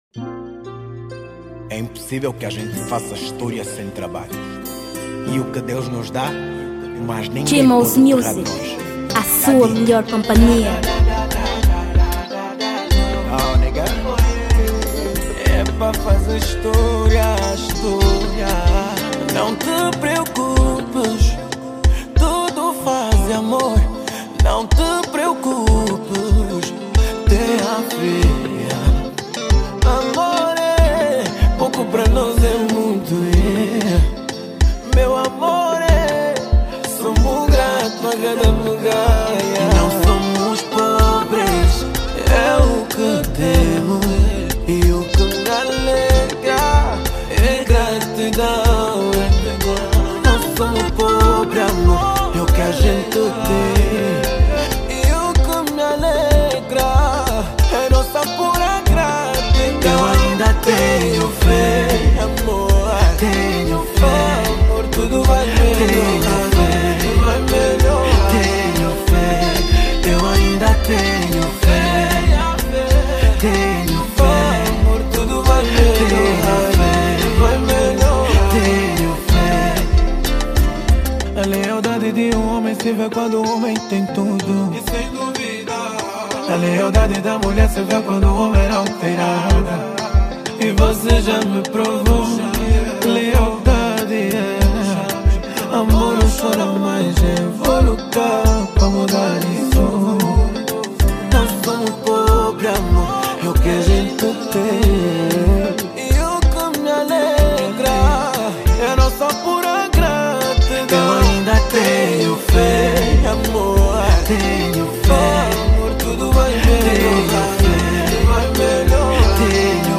2023 Gênero: Afro Beat Tamanho